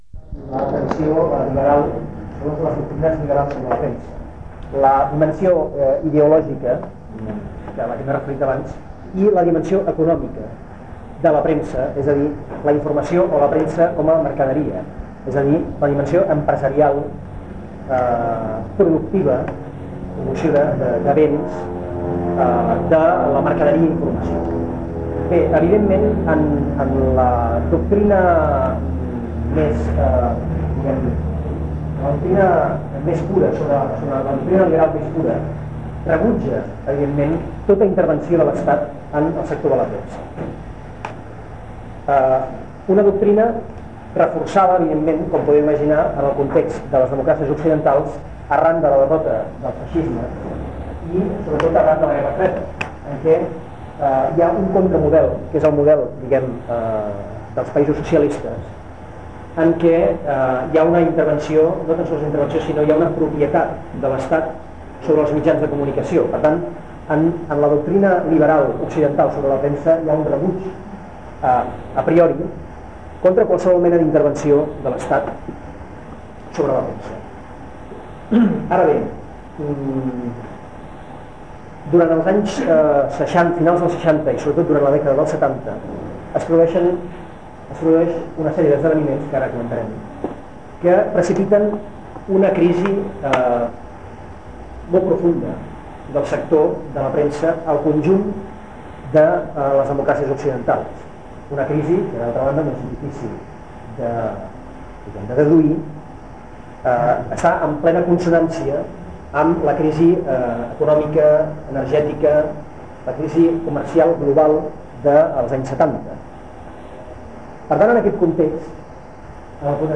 Corpus Oral de Registres (COR). EDUC3. Classe magistral
Aquest document conté el text EDUC3, una "classe magistral" que forma part del Corpus Oral de Registres (COR).